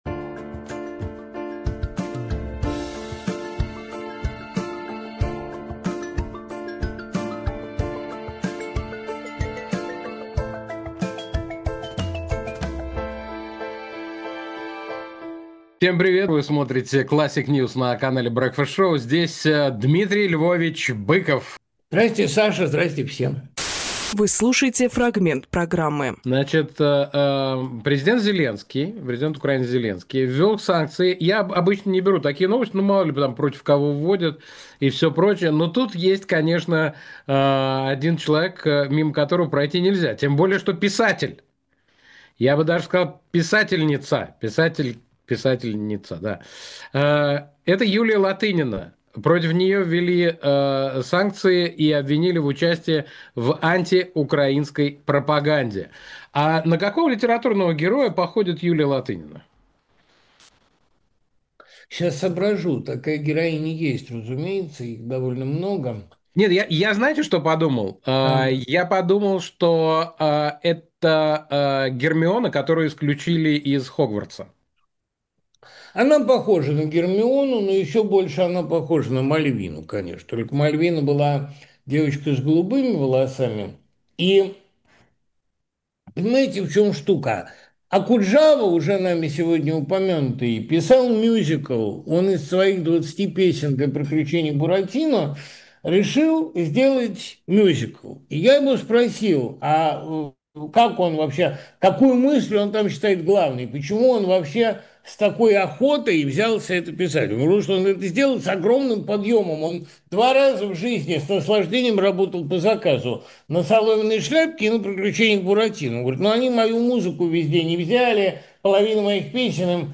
Фрагмент эфира от 20.07.25